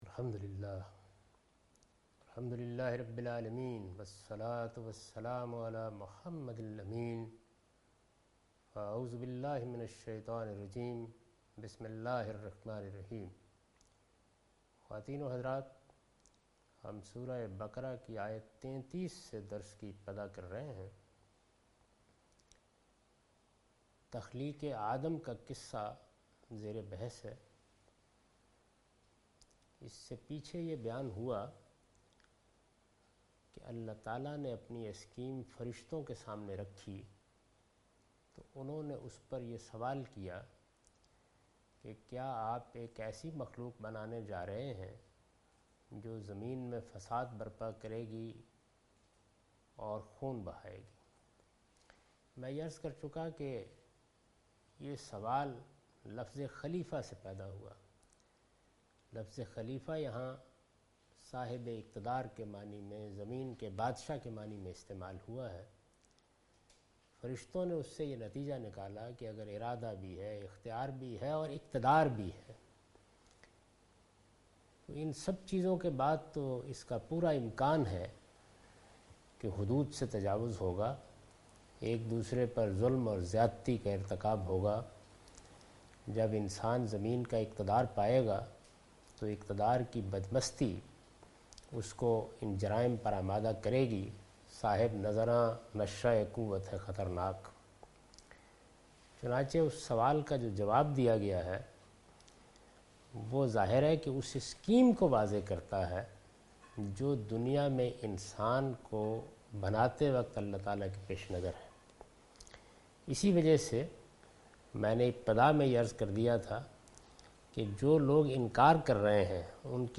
Surah Al-Baqarah - A lecture of Tafseer-ul-Quran – Al-Bayan by Javed Ahmad Ghamidi. Commentary and explanation of verse 33 and 34 (Lecture recorded on 25th April 2013).